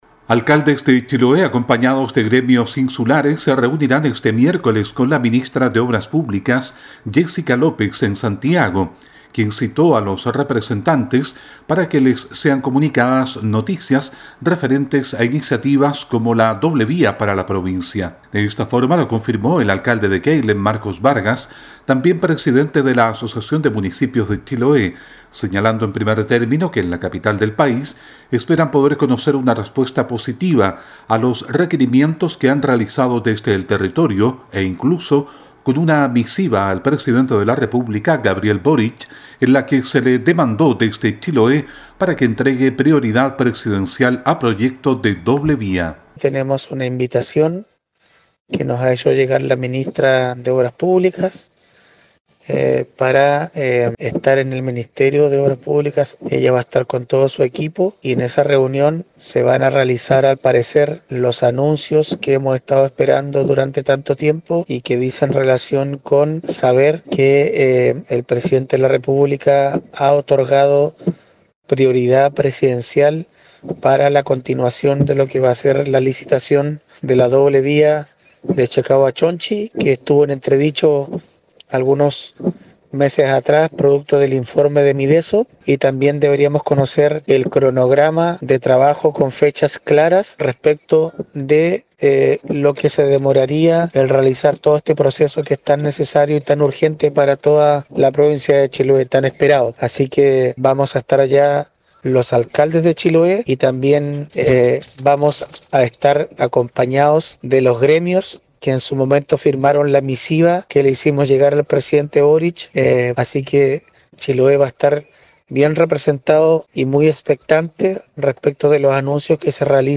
Los detalles en el siguiente reporte